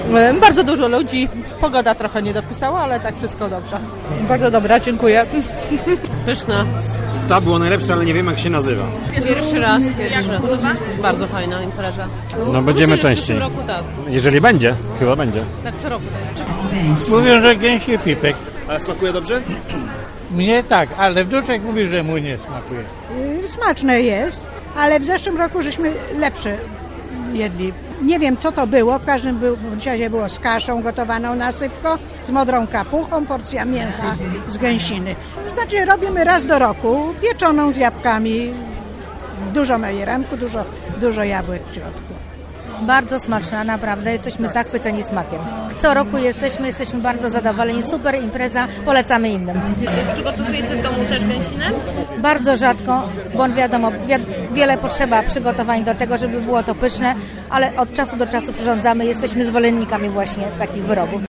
Nasi reporterzy odwiedzili Przysiek i zapytali jak smakują te specjały ?